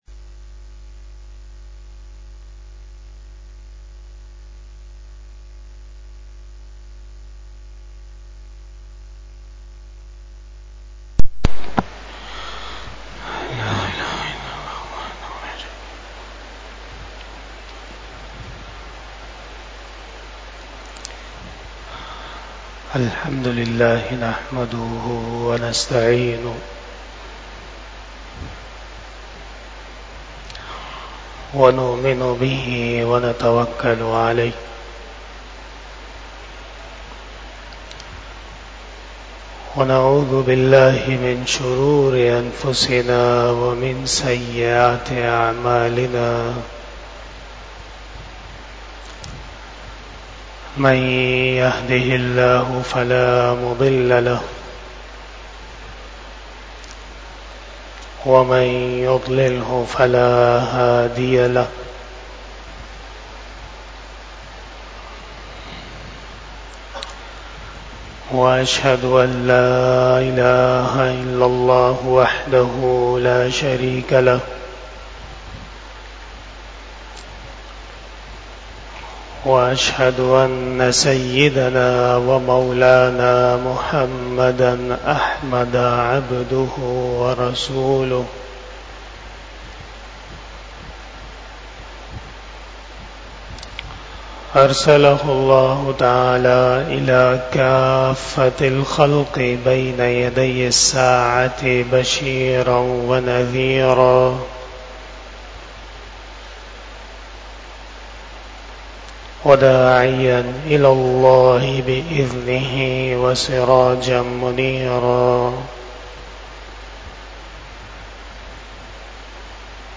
27 Bayan E Jummah 05 July 2024 (28 ZilHajjah 1445 HJ)